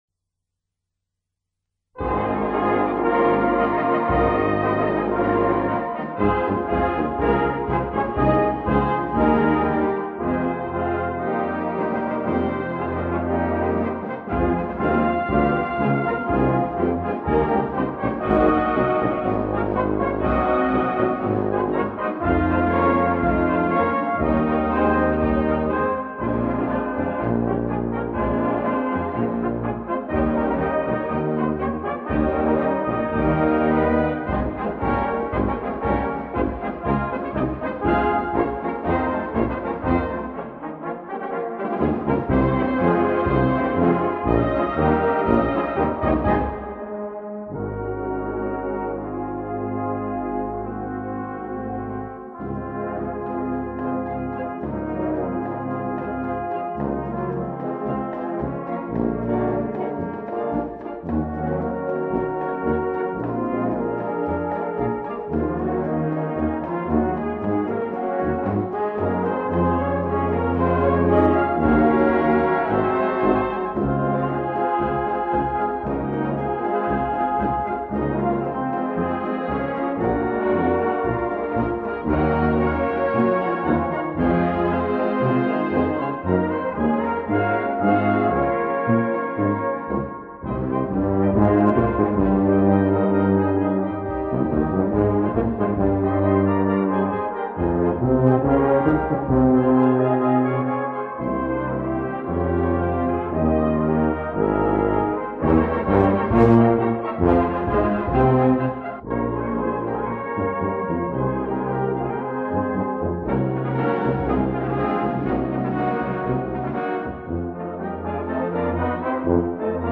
Gattung: Ouvertüre
Besetzung: Blasorchester
Strahlende Eröffnung und Schluß, eine Hommage an die